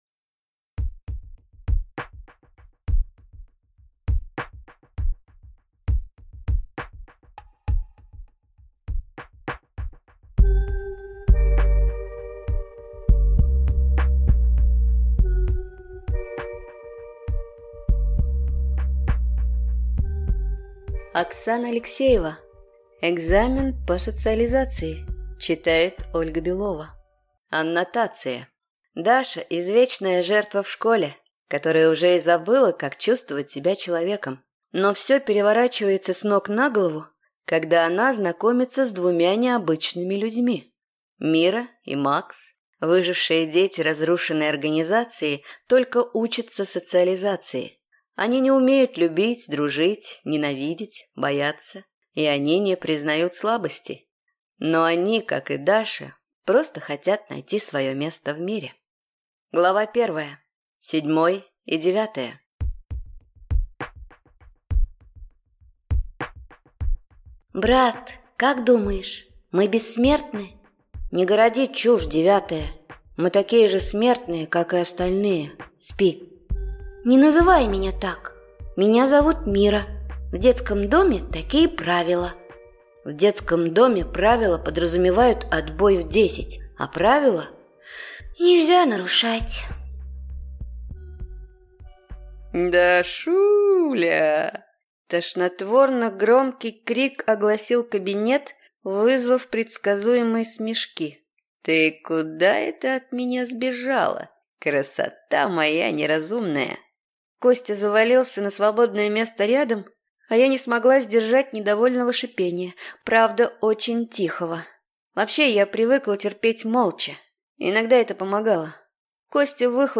Аудиокнига Экзамен по социализации | Библиотека аудиокниг